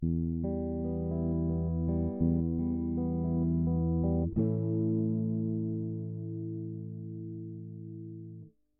Knacken bei Gitarrenaufnahme
Nun stehe ich allerdings vor einem Problem und zwar habe ich auf den Gitarren Aufnahmen im Höhenbereich ein Kratzen und kann nicht lokalisieren wie dass entsteht. Aufnahmeweg ist: Gitarre = Antelope 4 Synergy oder Universal Audio Apollo Twin = Imac (Ableton) folgendes habe ich schon ausgeschlossen/getestet: - Alle nicht benötigten Stromgeräte im Raum ausgesteckt.